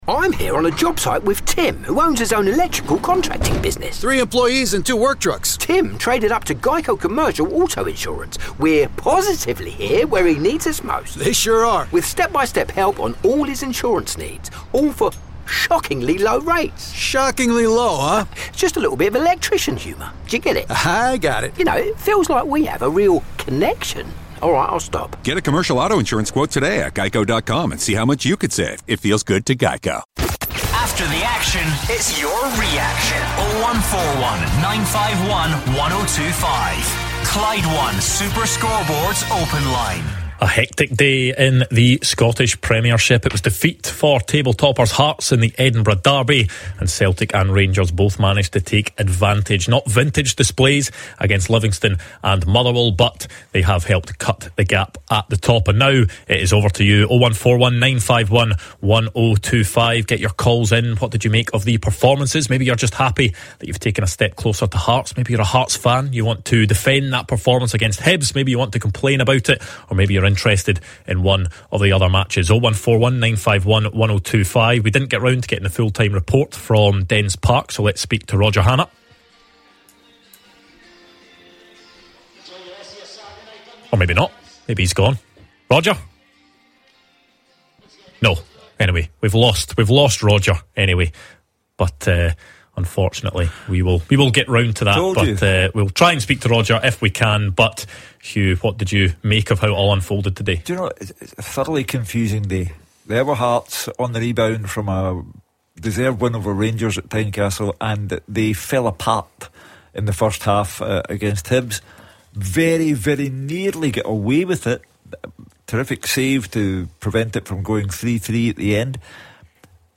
In this engaging conversation